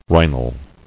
[rhi·nal]